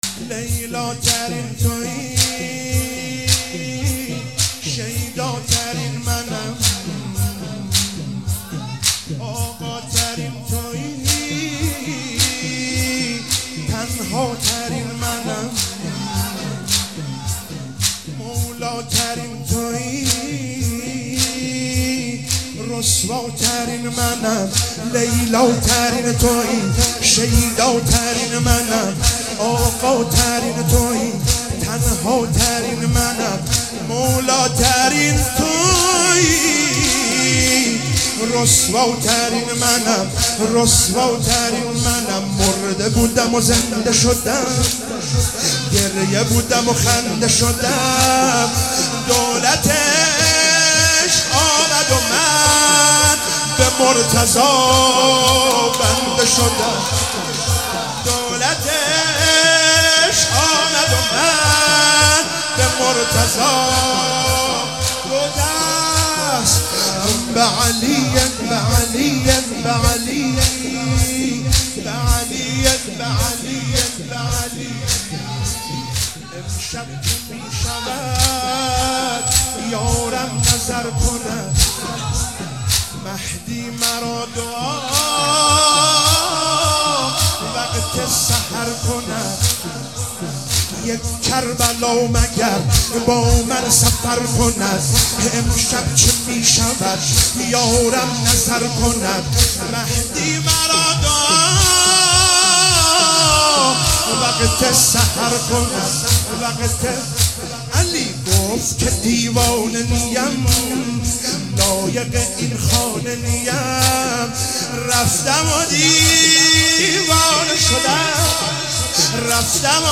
شب قدر